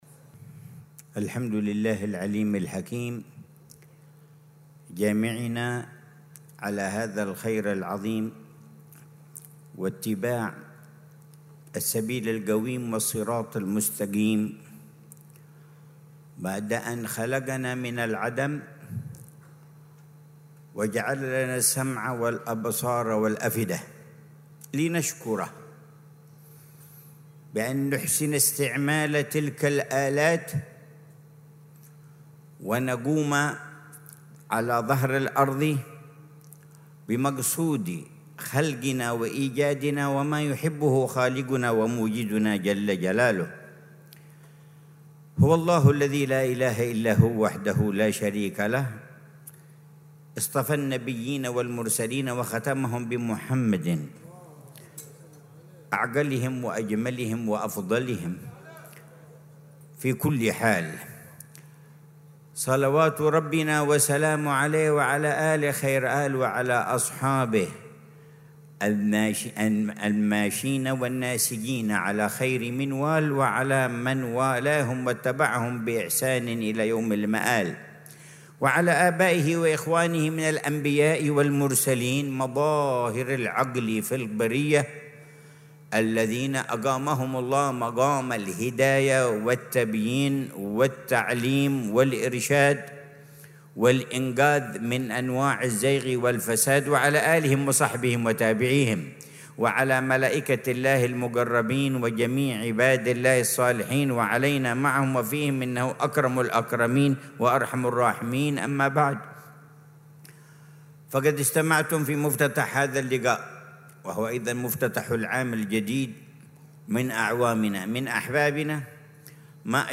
محاضرة في افتتاح ملتقى الدعاة السنوي (التاسع عشر)